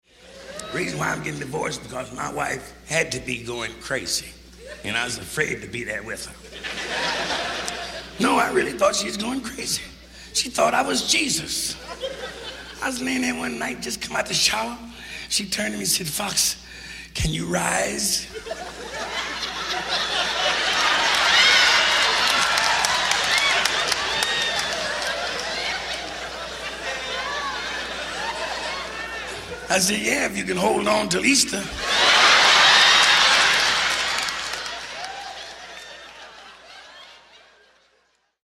a little comedy: